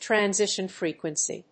transition+frequency.mp3